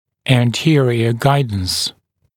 [æn’tɪərɪə ‘gaɪdns][эн’тиэриэ ‘гайднс]переднее ведение